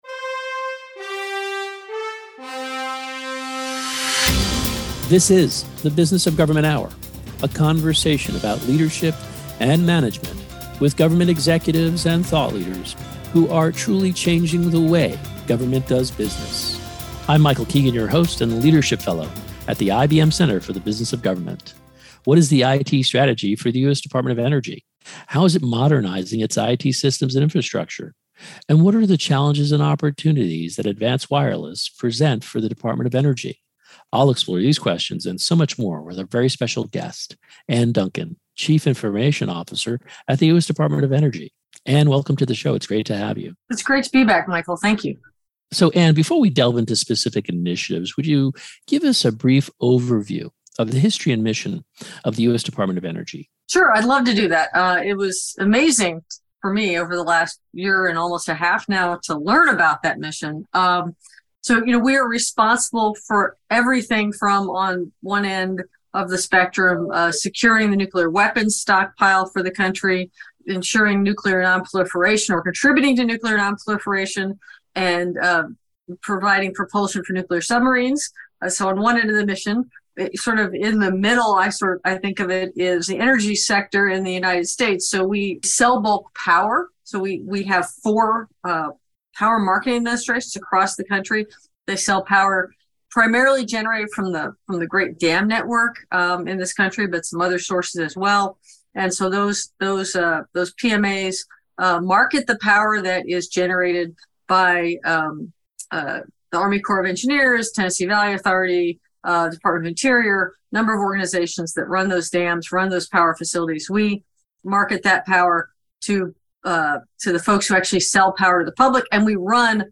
Radio Hour Share Podcast TV Hour The Business of Government Hour Stay connected with the IBM Center Download or Email Listen to the Business of Government Hour Anytime, Anywhere Video not available